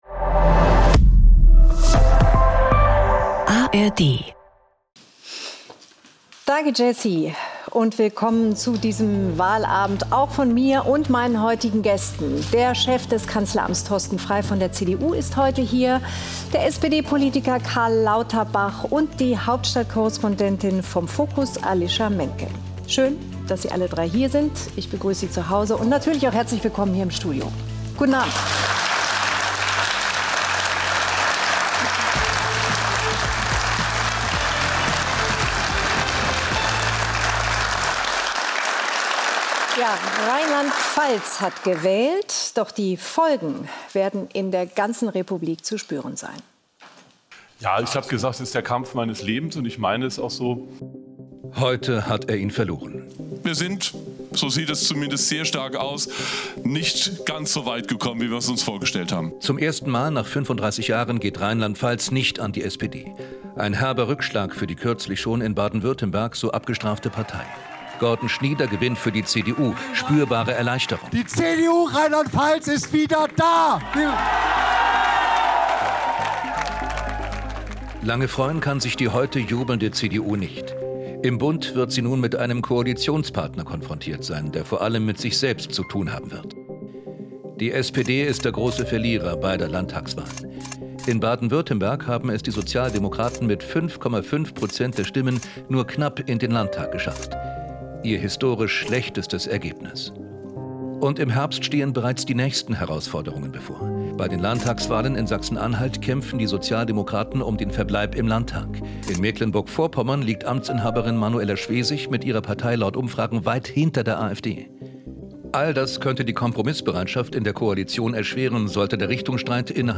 Caren Miosga analysiert mit ihren Gästen den Wahlabend: Kann Amtsinhaber Schweitzer seine Position trotz der Schwäche der SPD auf Bundesebene verteidigen?